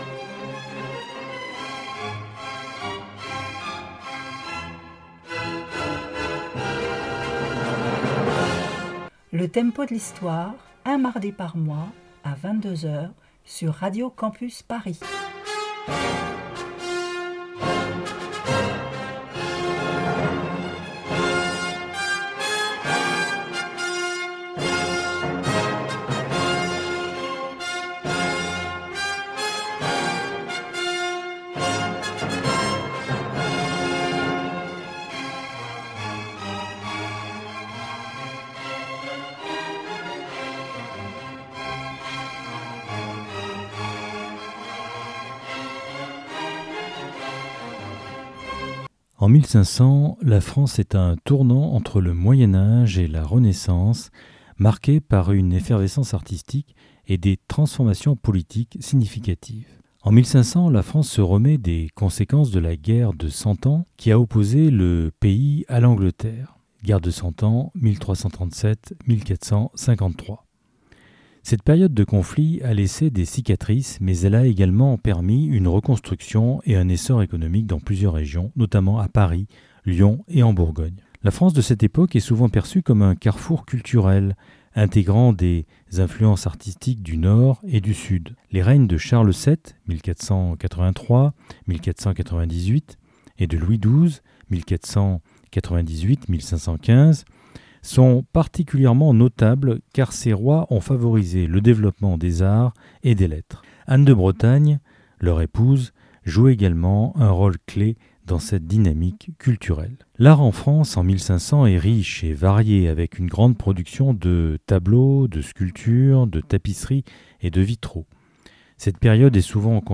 Musicale Pop & Rock